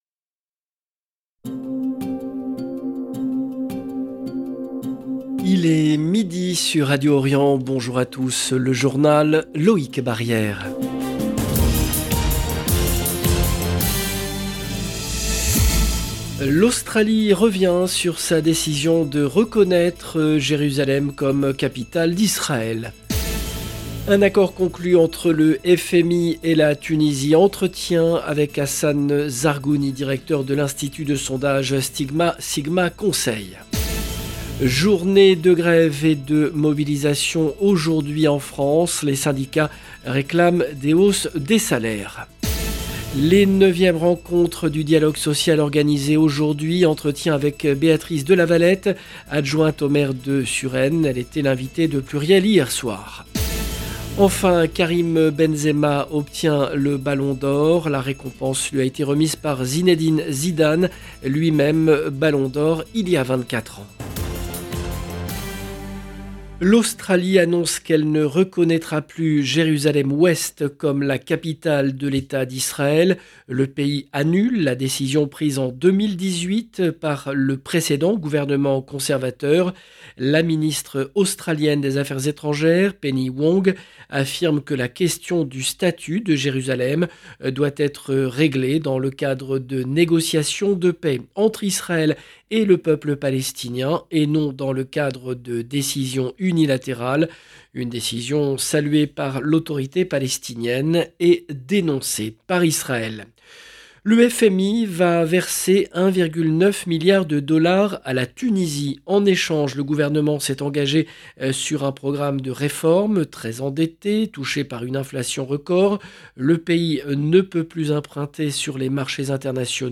Journal présenté